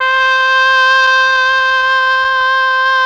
RED.OBOE  30.wav